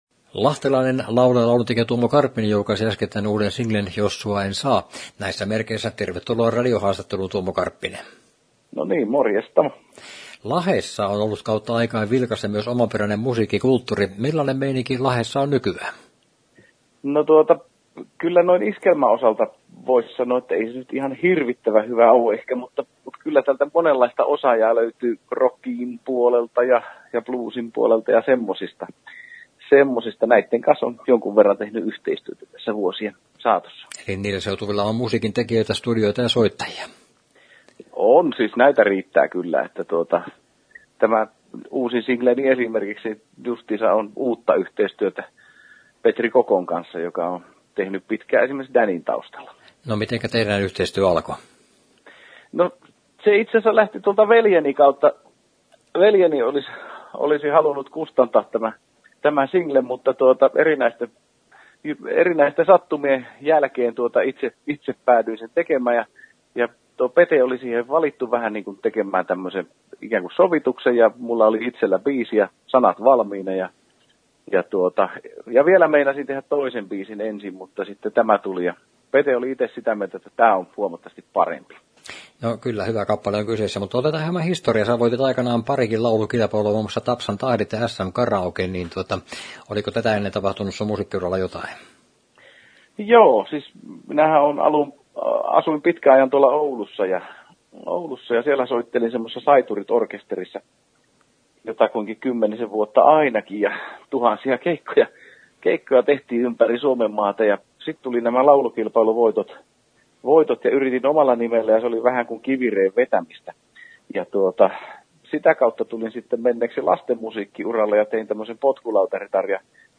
Haastattelu, Henkilökuvassa, Viihdeuutiset, Yleinen